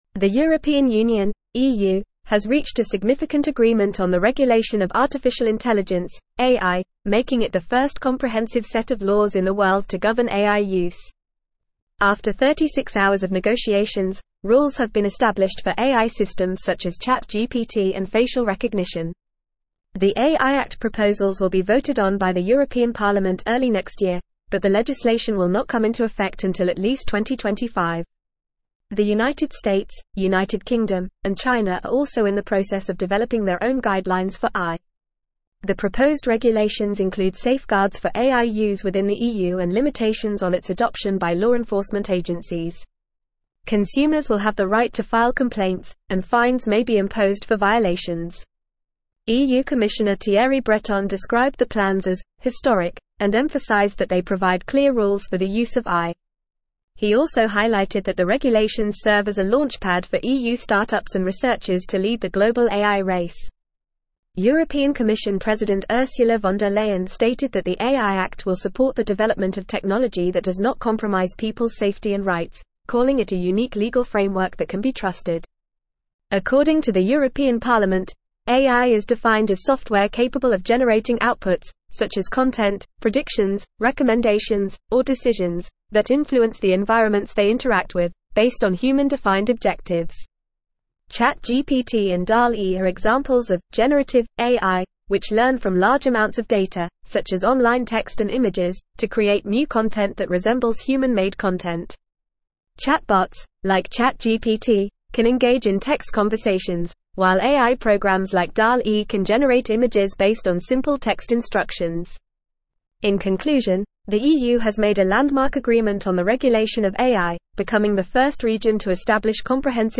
English-Newsroom-Advanced-FAST-Reading-EU-Reaches-Historic-Agreement-on-Comprehensive-AI-Regulations.mp3